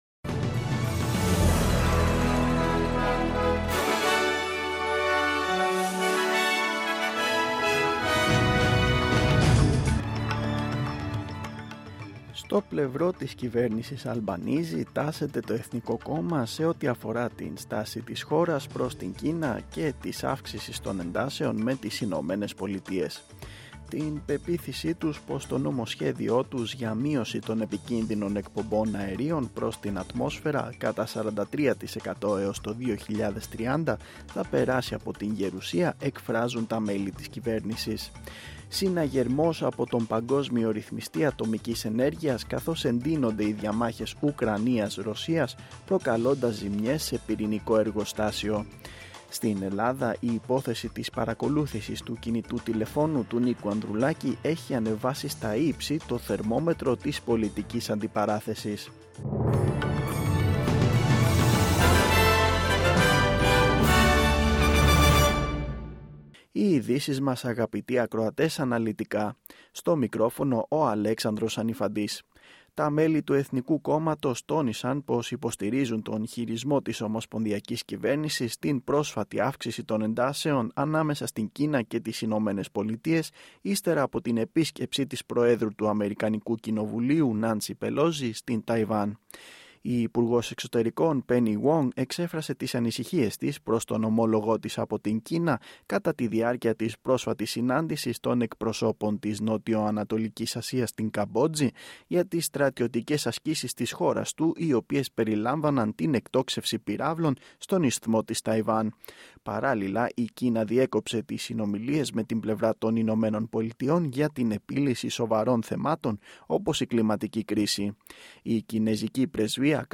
Δελτίο Ειδήσεων Κυριακή 7-8-2022
Το αναλυτικό δελτίο ειδήσεων του Ελληνικού προγράμματος της ραδιοφωνίας SBS, στις 16:00
News in Greek.